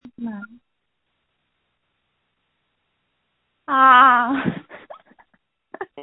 Scream #06992 0:18 UTC 6s 🔗
• When you call, we record you making sounds. Hopefully screaming.